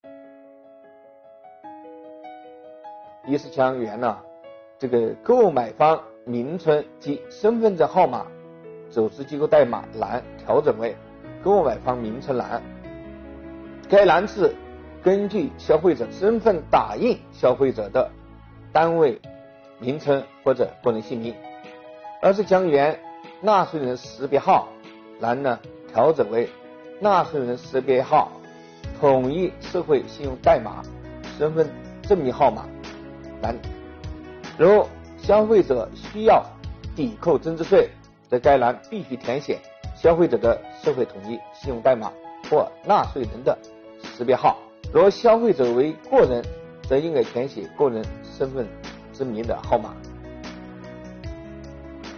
近日，国家税务总局推出“税务讲堂”课程，国家税务总局货物和劳务税司副司长张卫详细解读《办法》相关政策规定。